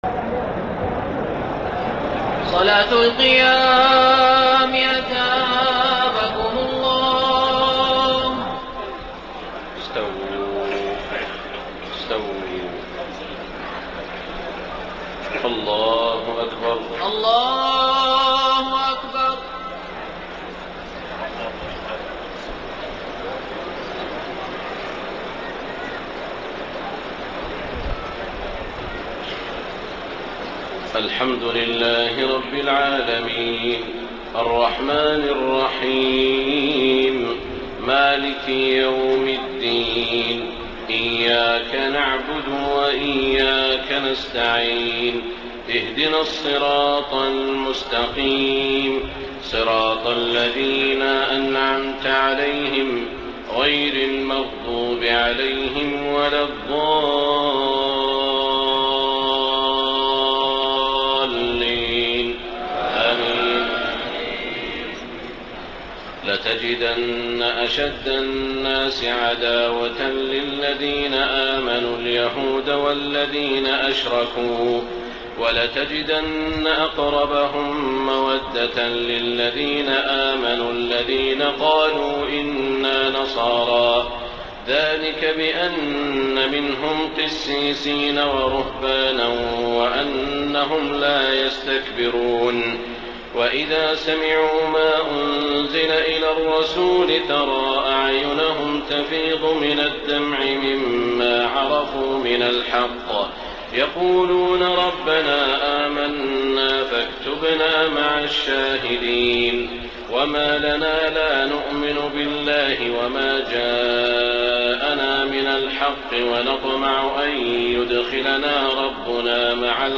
تهجد ليلة 27 رمضان 1433هـ من سورتي المائدة (82-120) و الأنعام (1-58) Tahajjud 27 st night Ramadan 1433H from Surah AlMa'idah and Al-An’aam > تراويح الحرم المكي عام 1433 🕋 > التراويح - تلاوات الحرمين